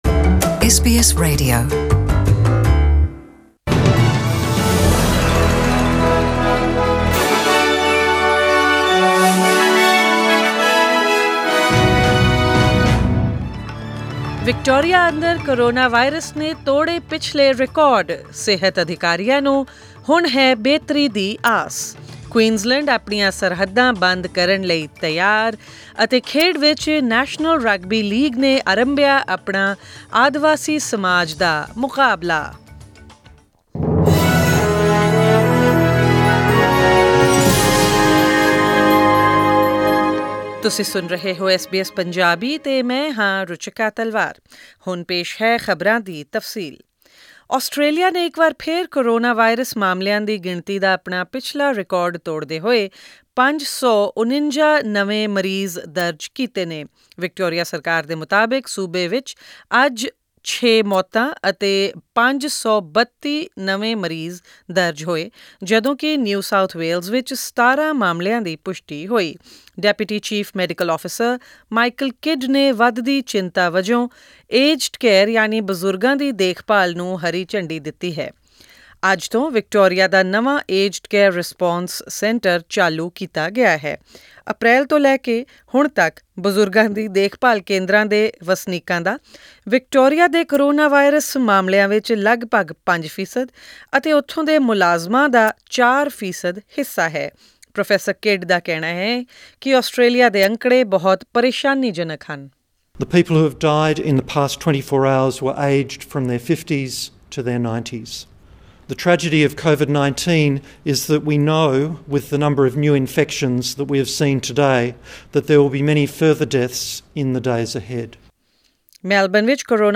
Australian News in Punjabi: 24 July 2020